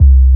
06BASS01  -L.wav